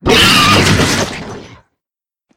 flesh_death_0.ogg